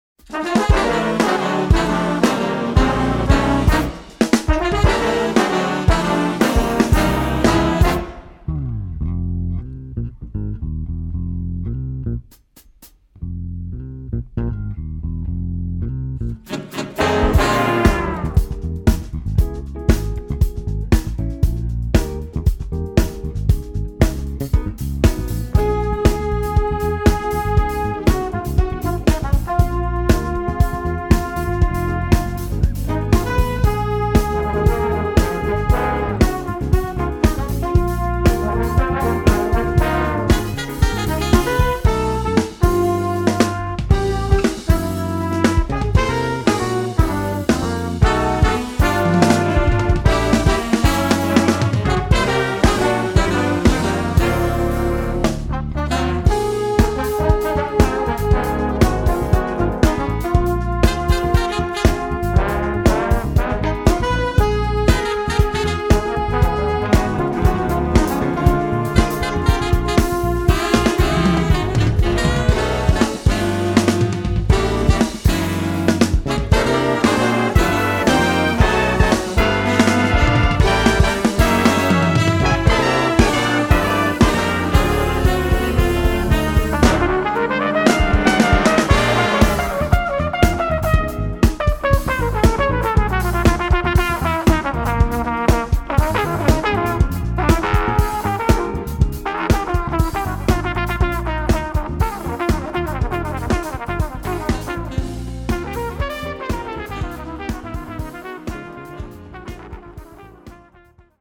Notated key Eb.
Reed setting: 2 Alto sax; 2 Tenor sax; 1 Baritone sax.
Notated tempo (bpm): Ca. 214-220